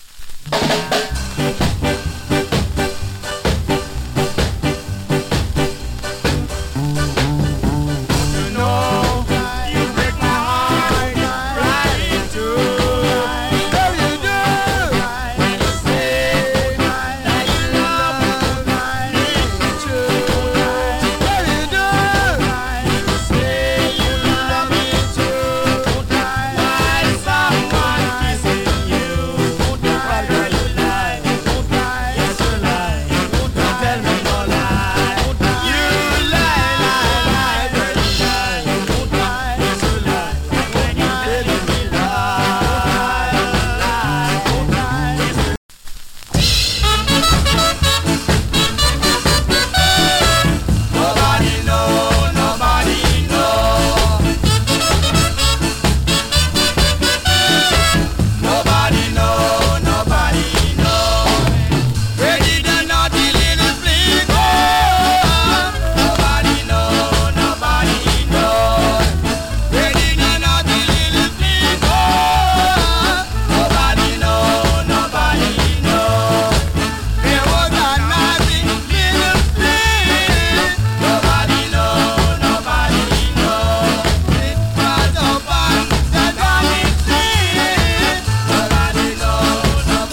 チリ、パチノイズ多数有り。
UP TEMPO な SKA